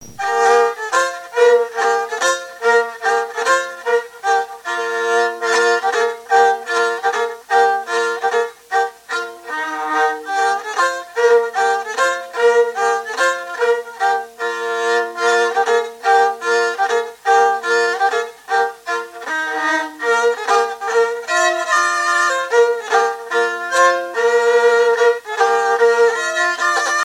Vendée (Plus d'informations sur Wikipedia)
Fonction d'après l'analyste danse : mazurka ;
Catégorie Pièce musicale inédite